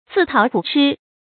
注音：ㄗㄧˋ ㄊㄠˇ ㄎㄨˇ ㄔㄧ
自討苦吃的讀法